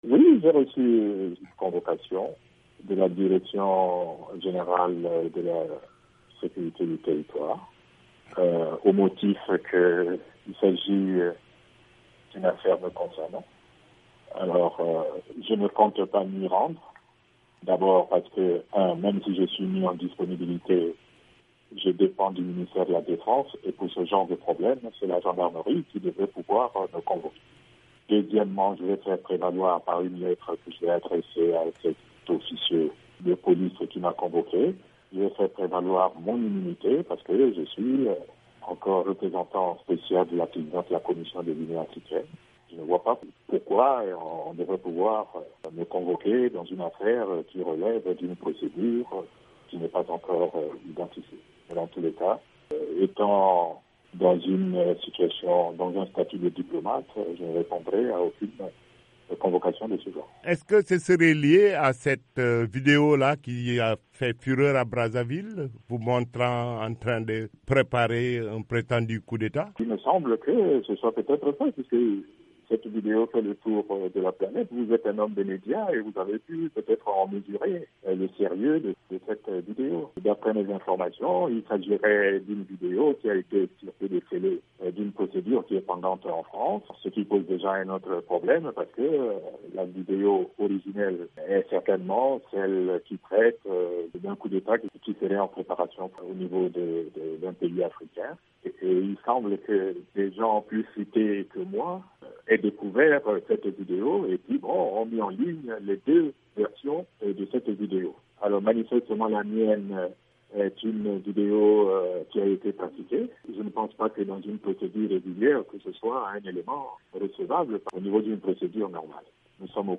Le général Jean-Marie Michel Mokoko, le rival de Denis Sassou Nguesso à la présidentielle du 20 mars, indique dans une interview à VOA Afrique, qu’il ne répondra pas à la convocation des services de sécurité qu’il a reçue.